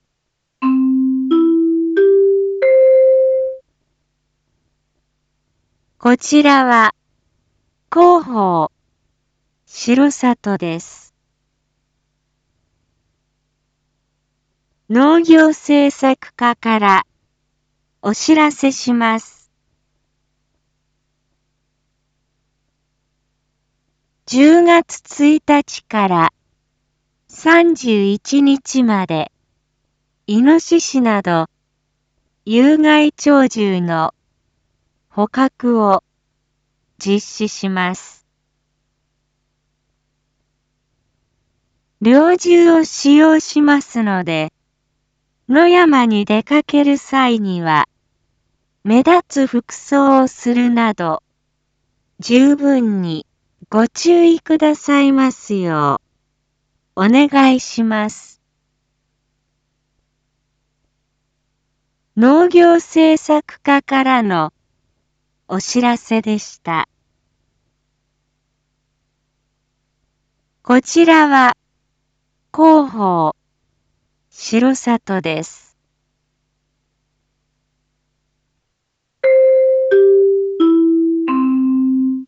一般放送情報
Back Home 一般放送情報 音声放送 再生 一般放送情報 登録日時：2022-10-14 19:01:23 タイトル：R4.10.14 19時放送分 インフォメーション：こちらは、広報しろさとです。